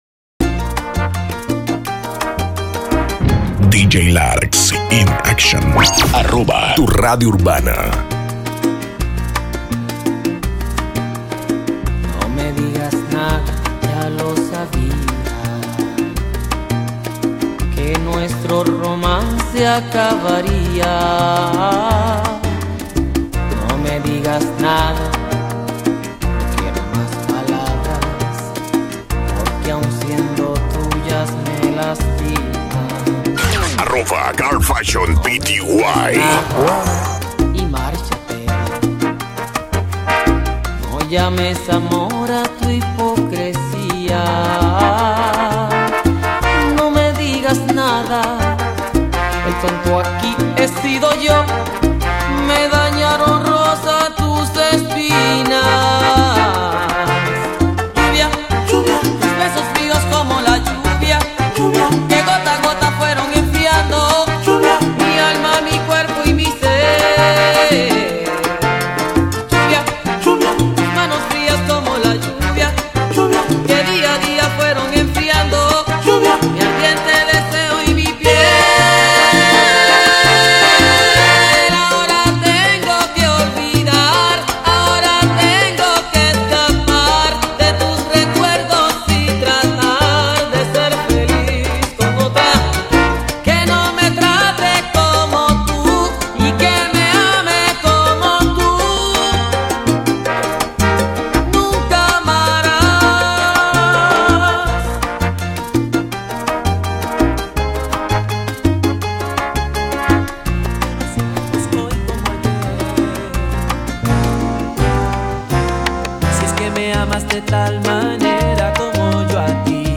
mix exclusivo